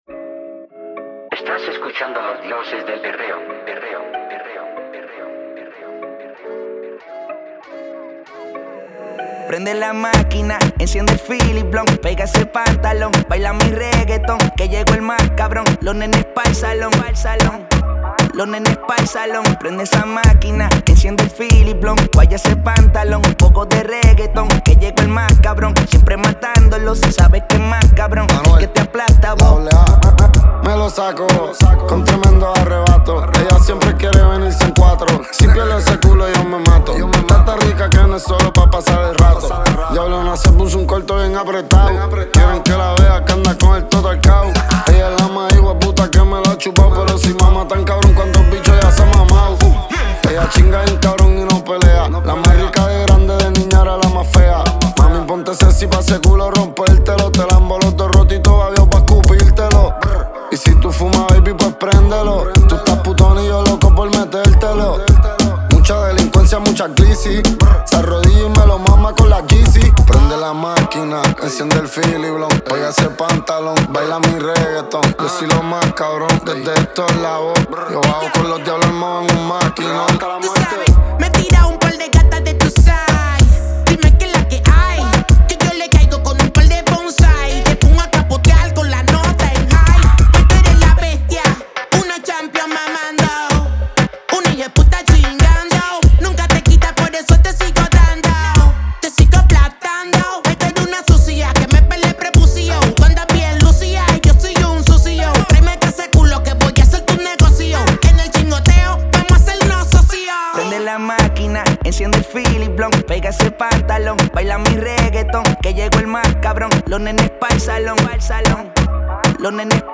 Genre: Ton Ton.